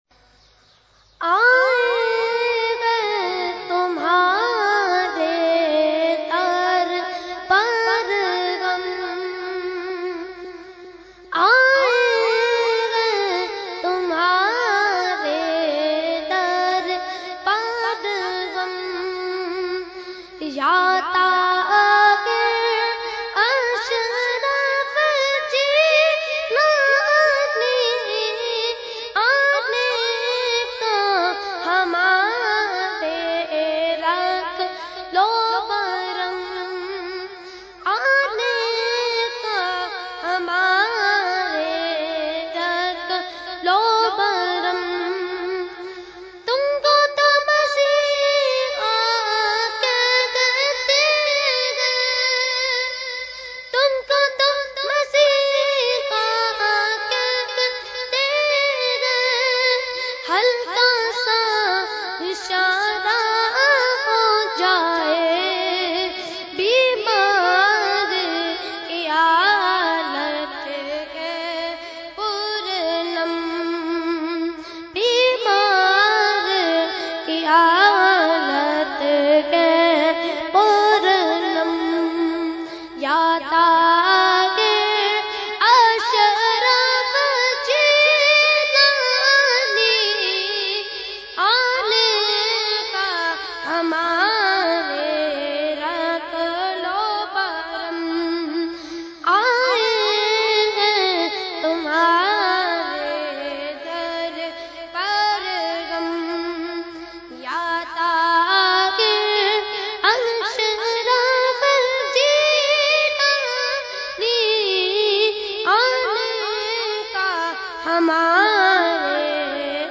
Category : Manqabat | Language : UrduEvent : Urs Qutbe Rabbani 2017